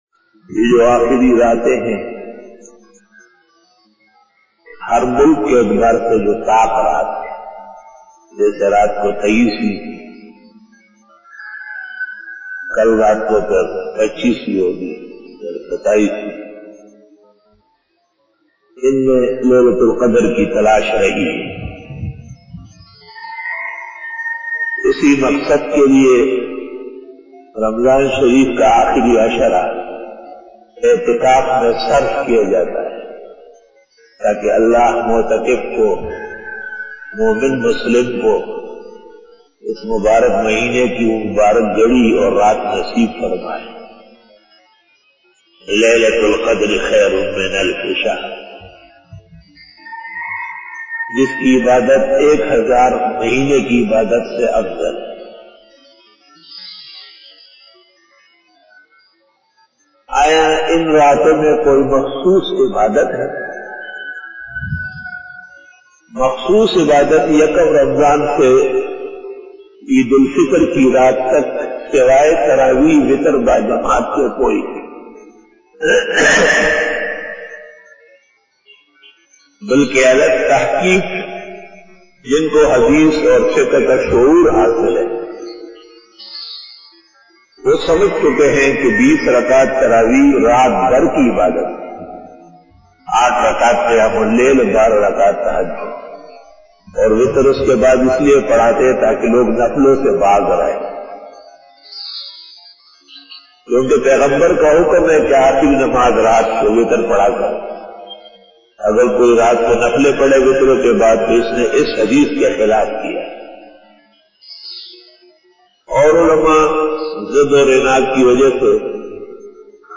After Fajar Byan
بیان بعد نماز فجر بروز جمعہ